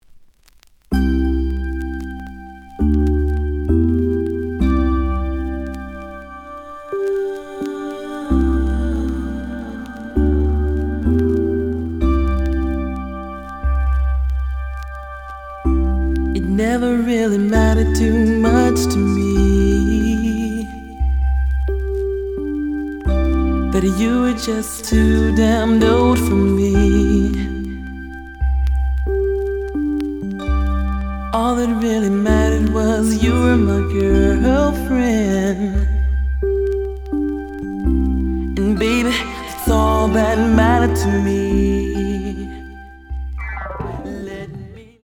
The audio sample is recorded from the actual item.
●Format: 7 inch
●Genre: Soul, 80's / 90's Soul